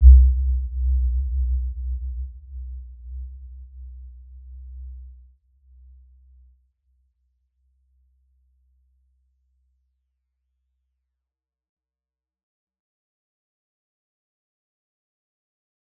Warm-Bounce-C2-mf.wav